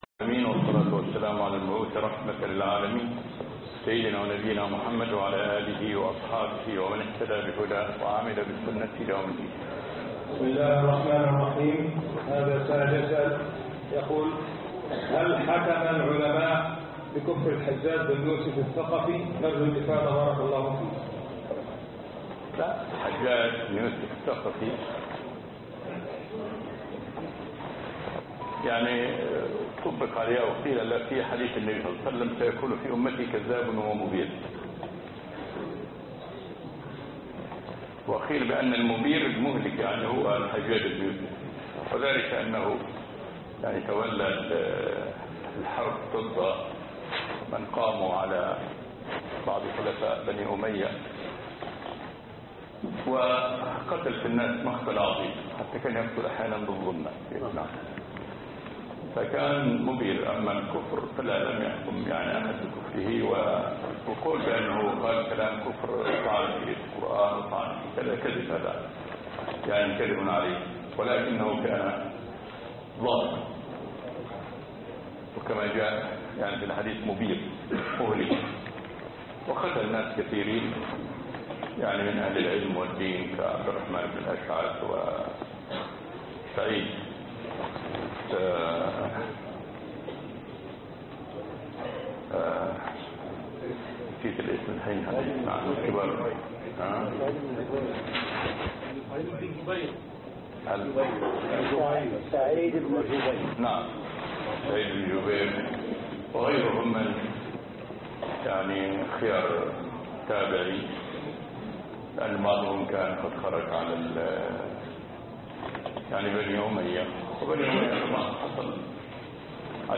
أسئلة المصلين( 14/11/2014) خطب الجمعة